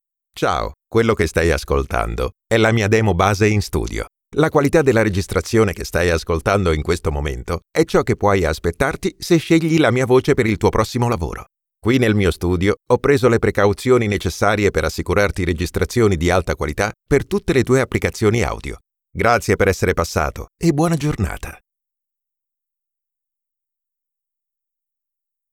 Male
Adult (30-50)
My voice is warm and sensual
Studio Quality Sample